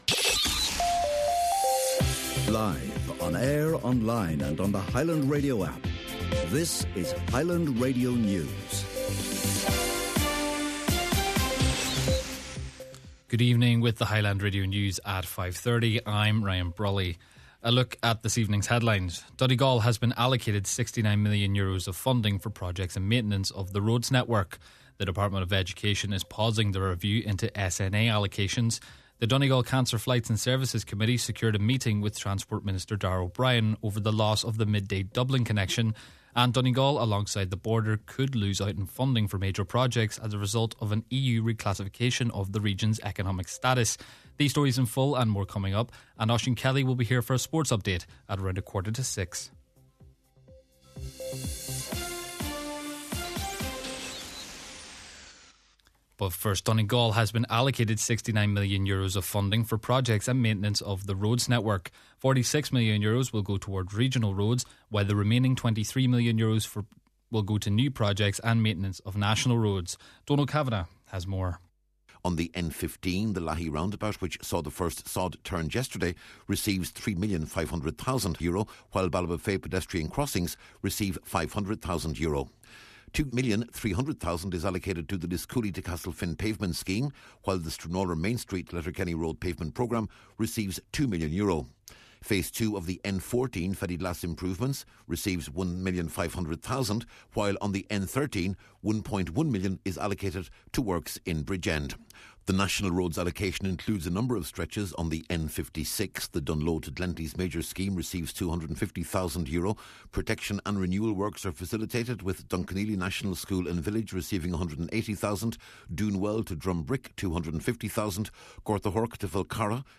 Main Evening News, Sport and Obituary Notices – Tuesday, February 17th